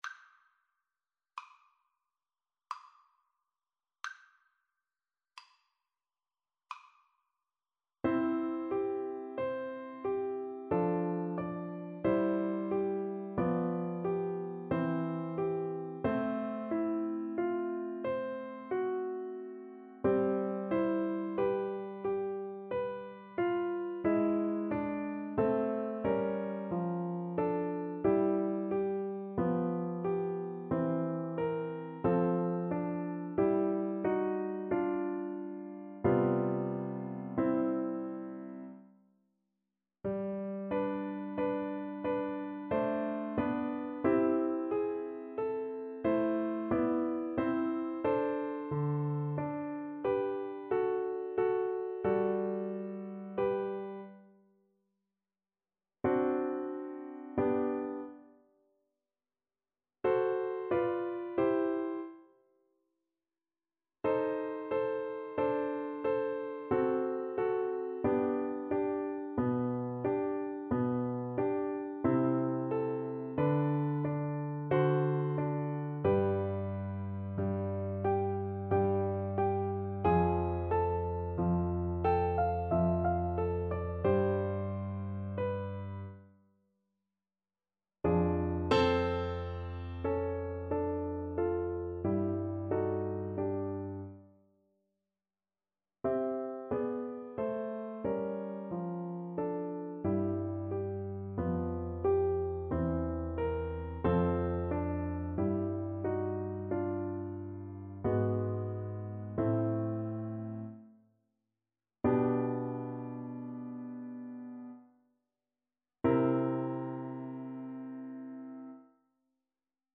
Play (or use space bar on your keyboard) Pause Music Playalong - Piano Accompaniment Playalong Band Accompaniment not yet available reset tempo print settings full screen
Adagio =45
C major (Sounding Pitch) (View more C major Music for Viola )
3/4 (View more 3/4 Music)
Classical (View more Classical Viola Music)